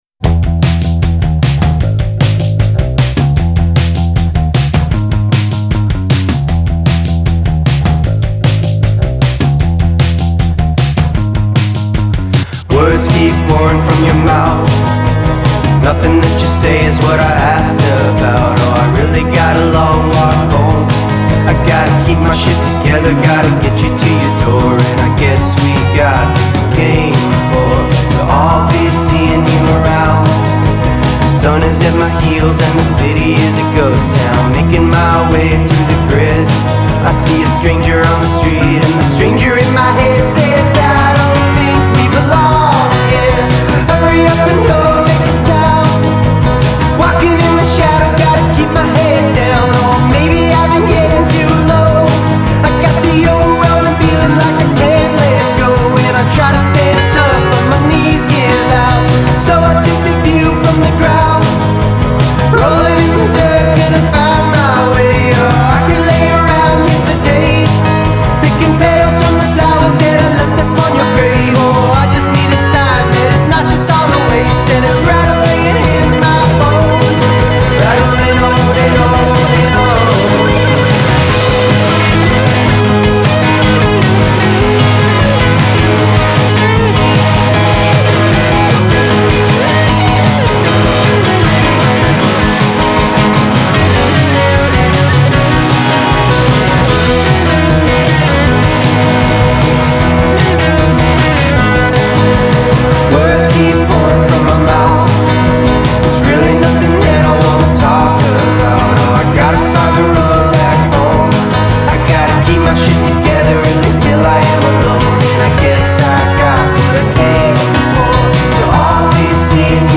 enticing & relentless
We begin with a bass solo, which doubles as an introduction